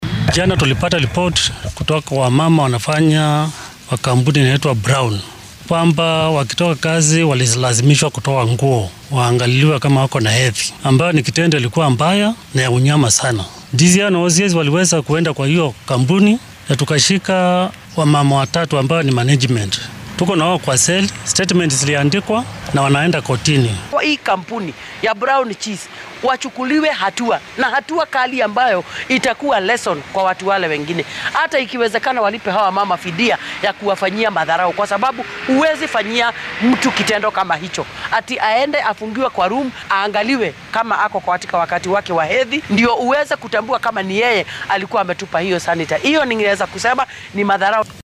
Booliiska Kiambu iyo mid ka mid ah haweenka deegaankaasi ayaa dhacdadan ka hadlay.